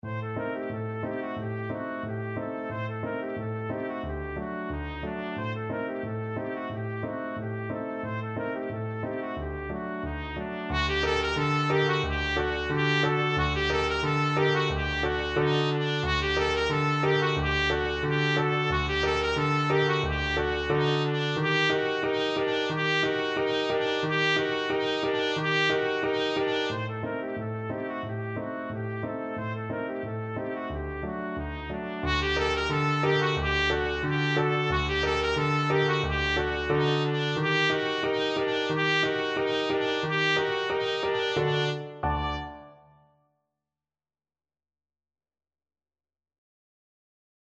Traditional Trad. Abdala (Balkan Dance) Trumpet version
2/4 (View more 2/4 Music)
G minor (Sounding Pitch) A minor (Trumpet in Bb) (View more G minor Music for Trumpet )
Presto = 180 (View more music marked Presto)
Trumpet  (View more Easy Trumpet Music)
Traditional (View more Traditional Trumpet Music)
world (View more world Trumpet Music)